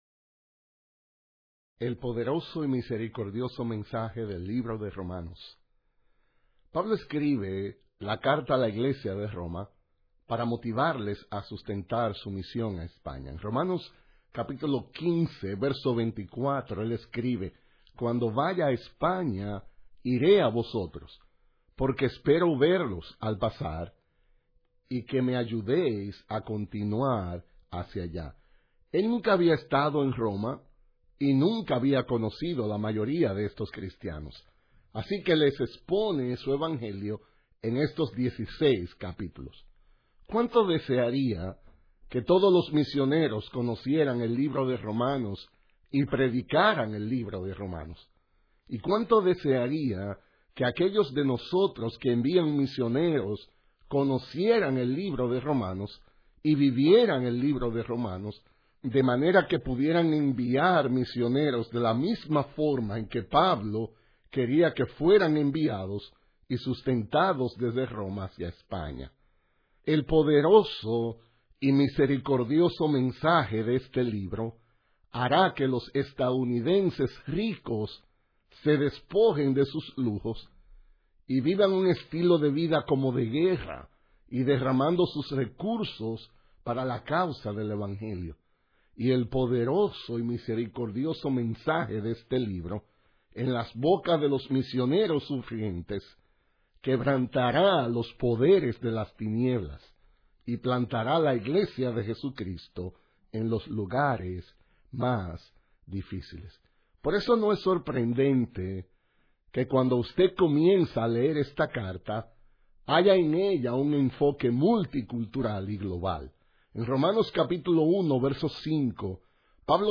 Message by John Piper